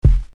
Kicks
nt kick 10.wav